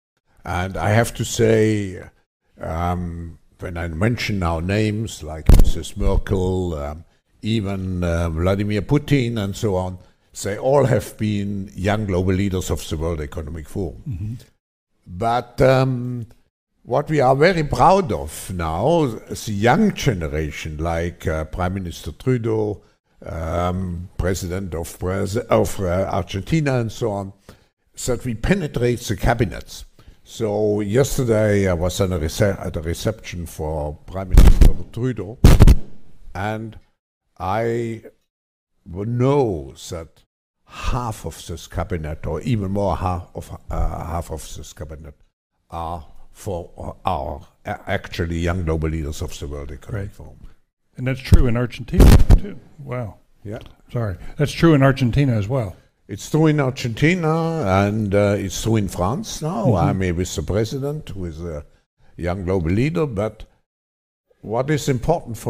Klaus Schwab, World Economic Forum Founder & Executive Chairman
Clip from 2017 at Harvard John F. Kennedy School of Government
Klaus Schwab to GloboCap Promo Man David Gergen:
KlausSchwabAtHarvard-WePenetrateTheCabinets-2017.mp3